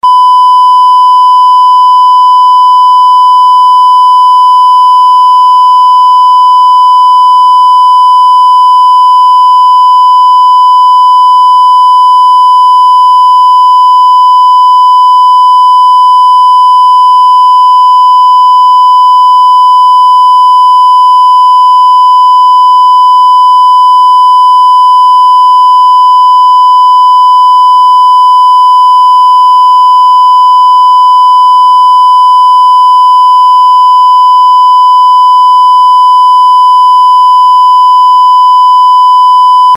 最近は見かける機会が少なくなりました放送終了後のカラーバー画面のときに流れる「ピー」といった音。